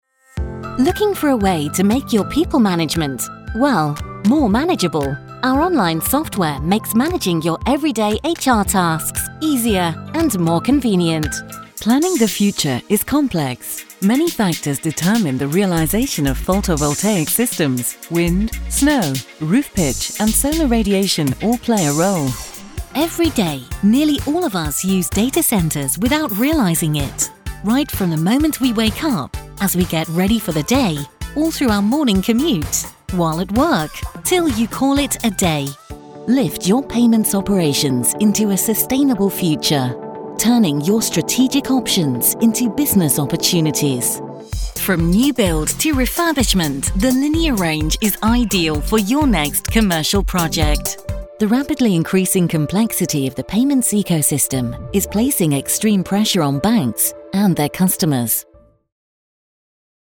Vidéos explicatives
An experienced and versatile British female voice over, recording for clients around the globe from my professional home studio in the UK.
My voice has been described as warm with gentle gravitas – I love performing deep and modulated narrations – though I can definitely switch things up for more fun and bubbly reads.
Home studio with purpose-built floating isolation booth